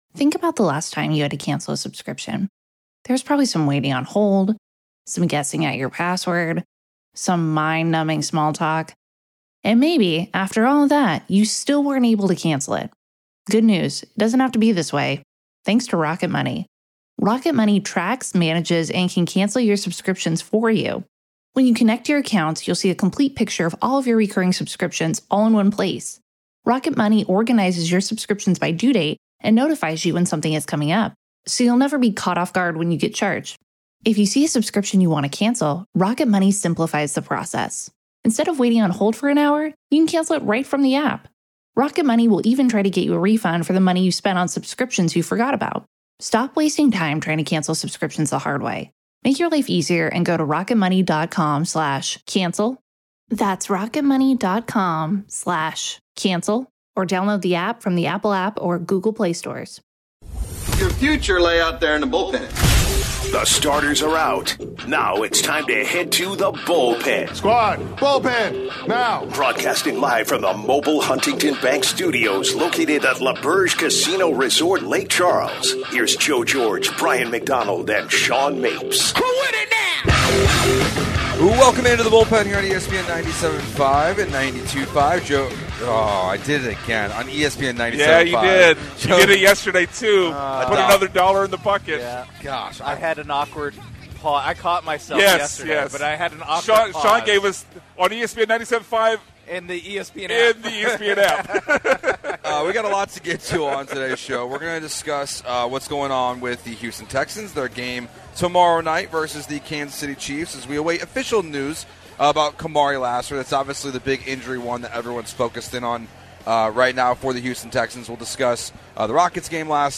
12/06/25 Hour 1 (Live from L'auberge in Lake Charles)- Can the Texans win at Arrowhead? + Should the Rockets think about trading for Giannis?